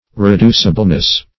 Reducibleness \Re*du"ci*ble*ness\, n. Quality of being reducible.